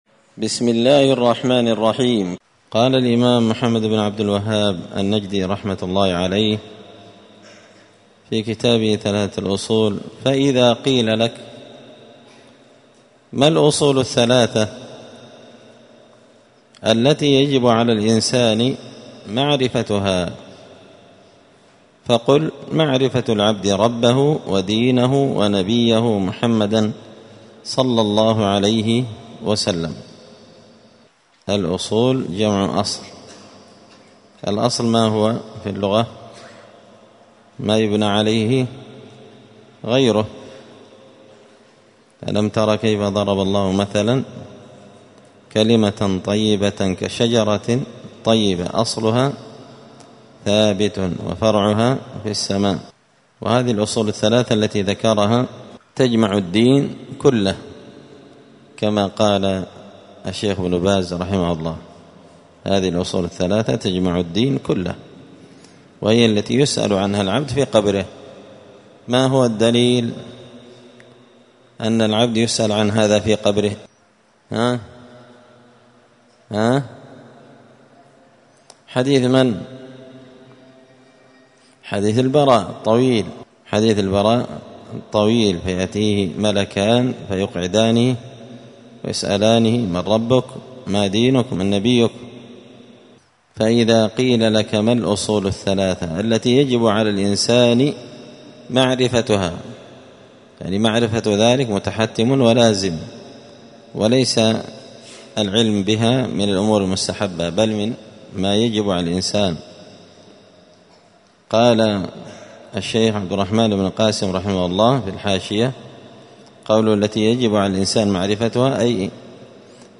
مسجد الفرقان قشن_المهرة_اليمن 📌الدروس الأسبوعية